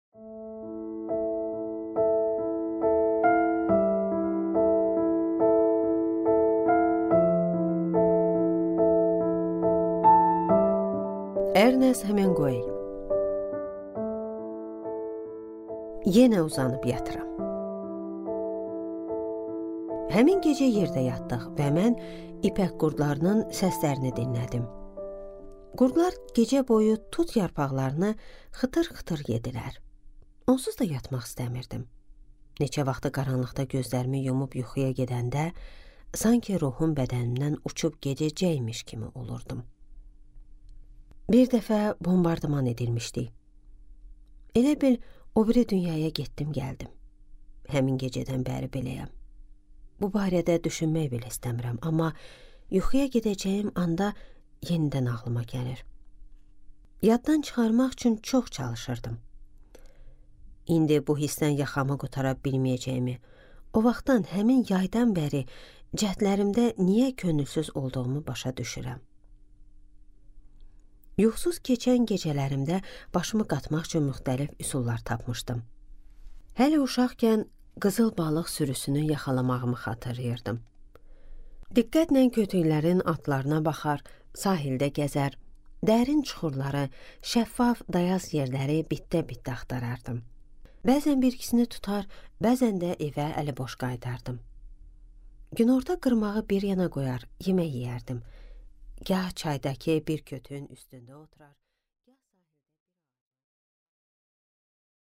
Аудиокнига Yenə uzanıb yatıram | Библиотека аудиокниг